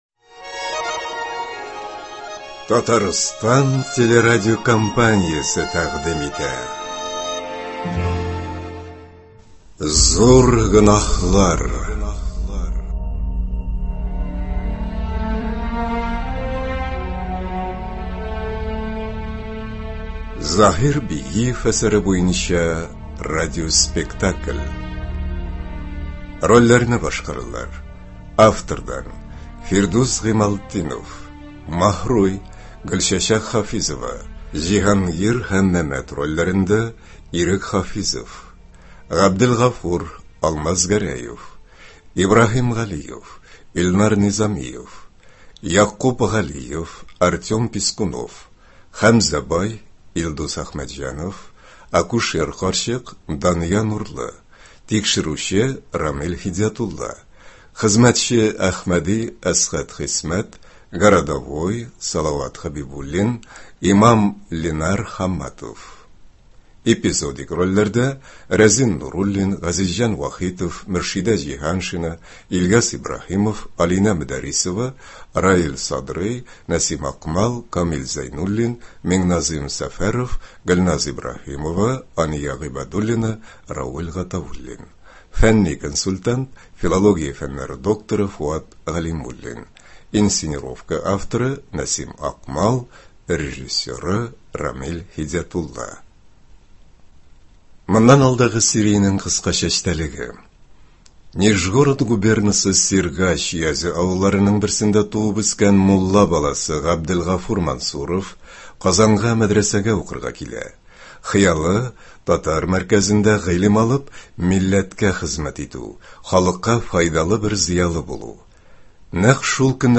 “Зур гөнаһлар”. Радиоспектакль.